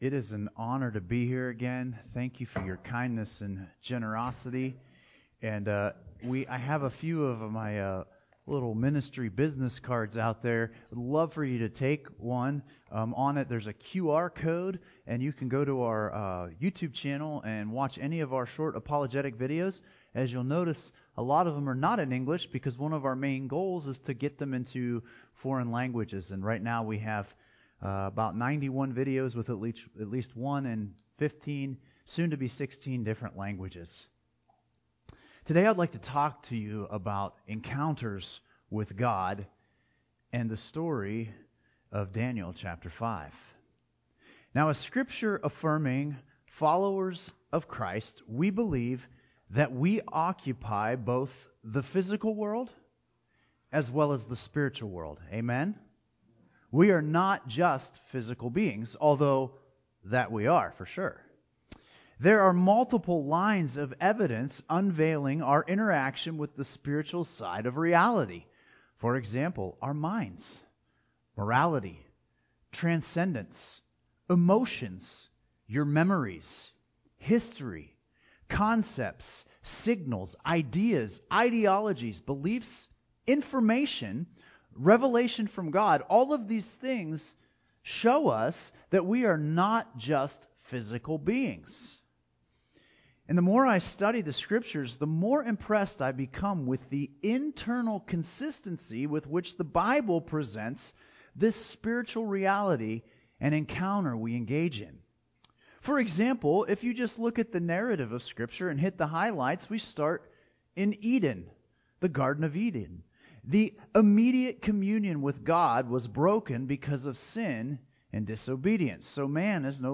One of our mission partners shares a message on the spiritual realm.